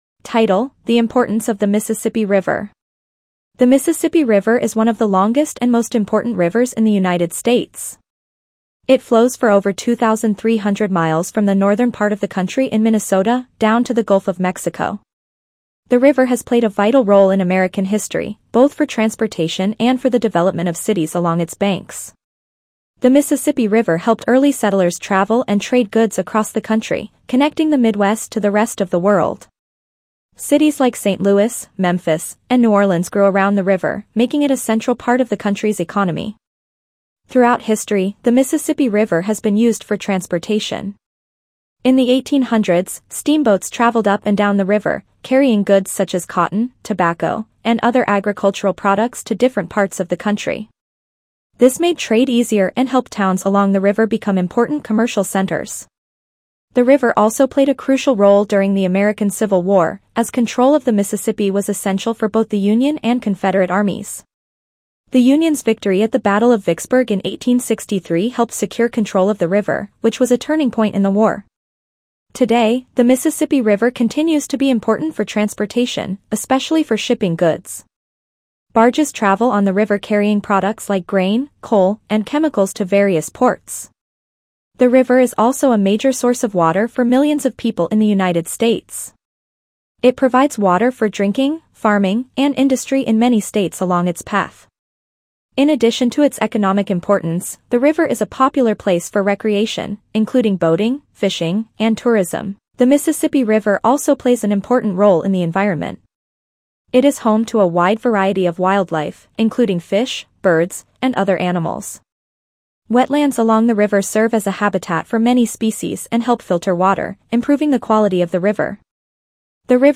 Reading B1 - The Importance of the Mississippi River
Reading-Lesson-B1-Mississippi-River.mp3